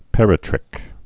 (pĕrĭ-trĭk)